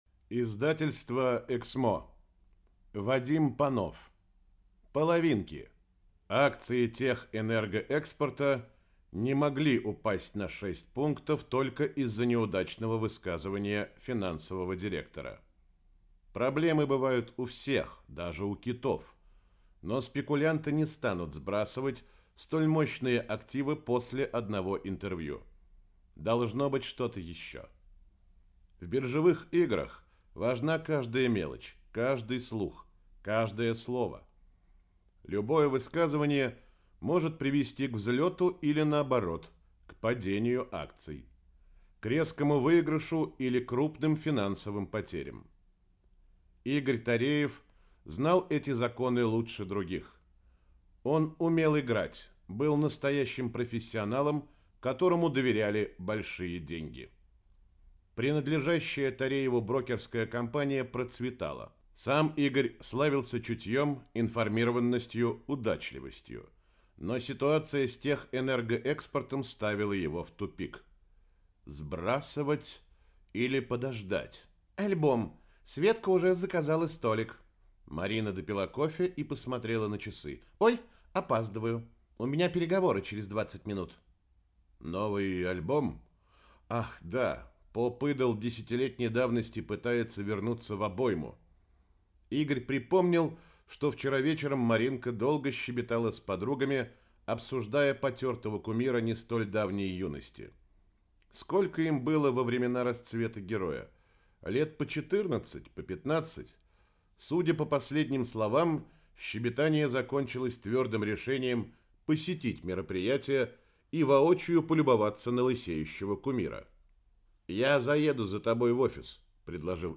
Аудиокнига Половинки | Библиотека аудиокниг